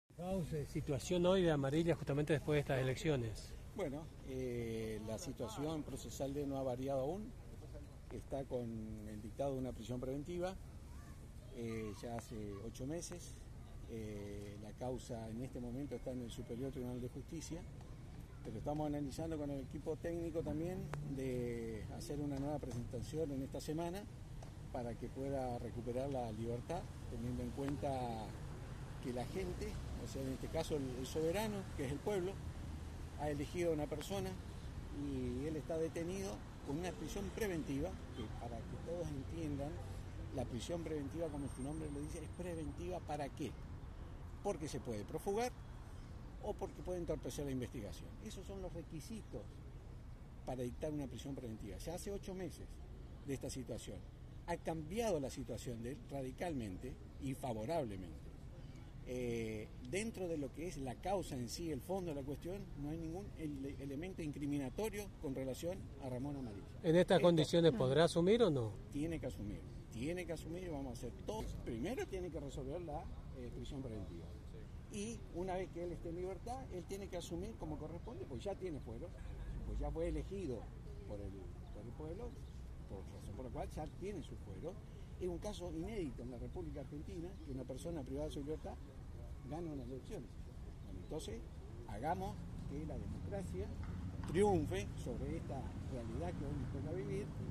Ayer, en conferencia de prensa se realizó este anuncio.